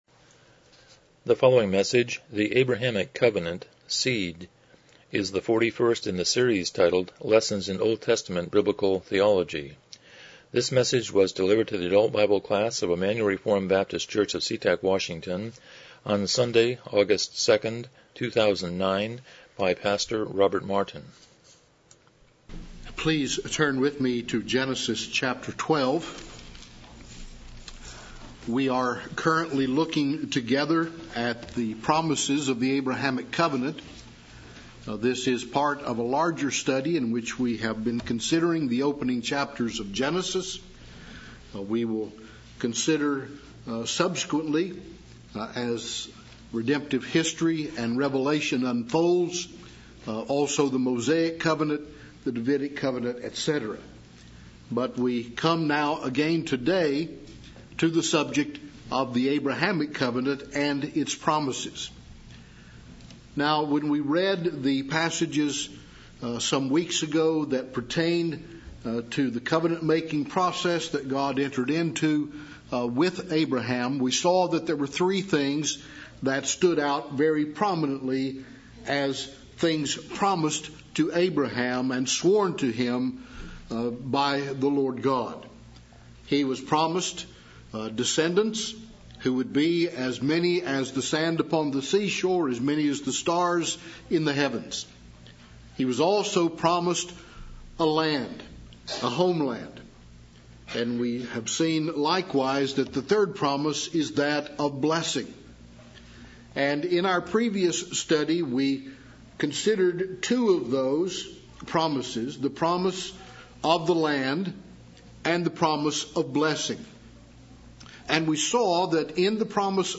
Lessons in OT Biblical Theology Service Type: Sunday School « 69 Chapter 11.6